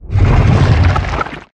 Sfx_creature_shadowleviathan_swimgrowl_os_03.ogg